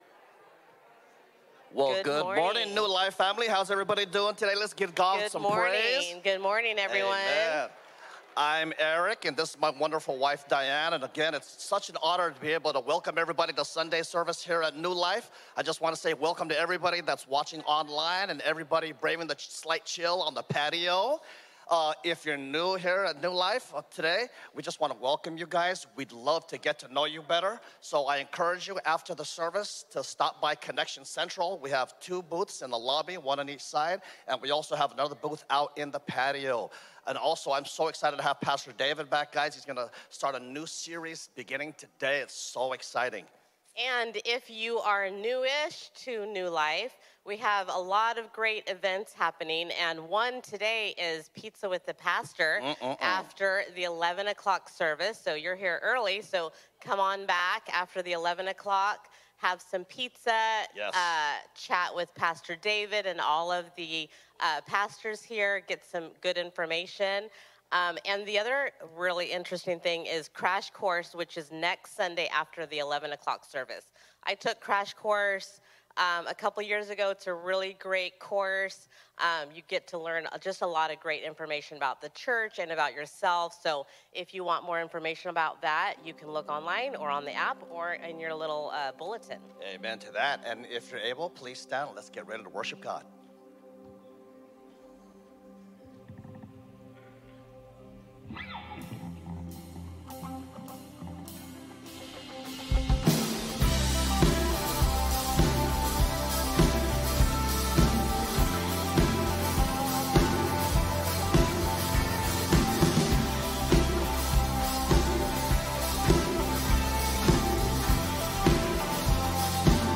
A message from the series "More and Better."